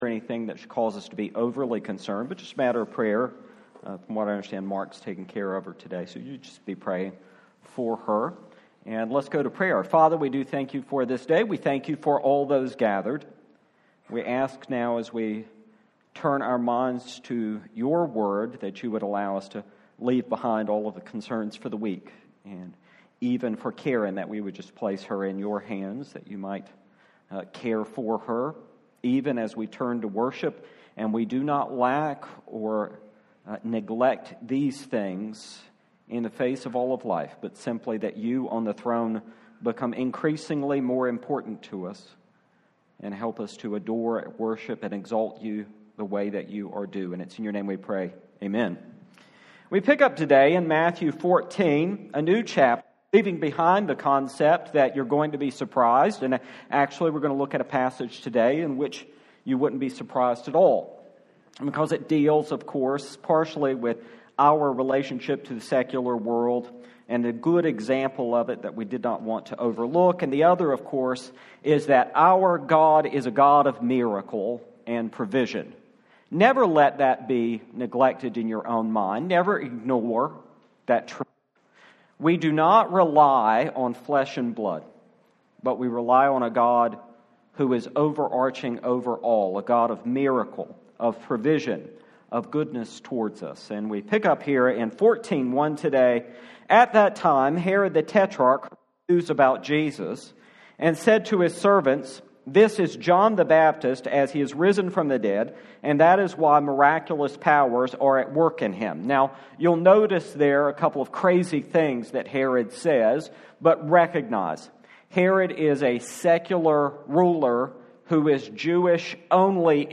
The Gospel of Matthew - Feeding the 5000 - Church of the Living Christ